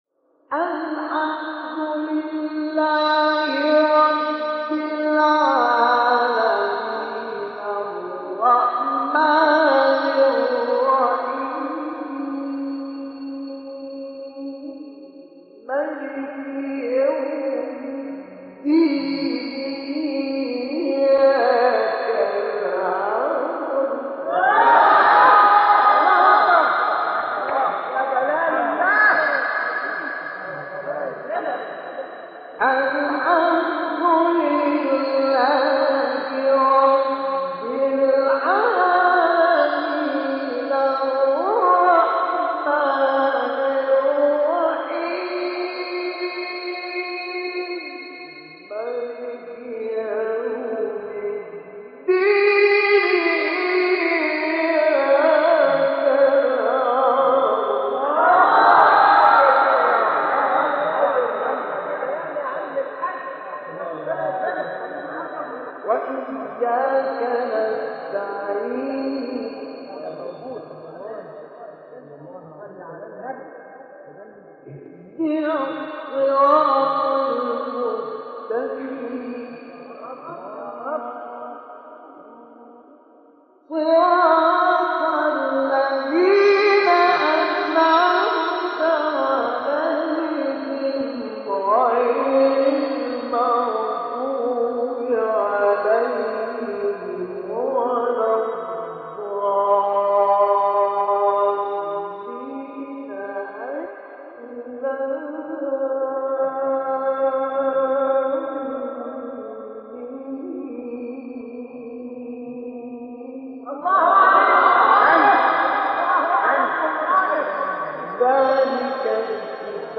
تلاوت سوره حمد
سوره حمد با صوت مصطفی اسماعیل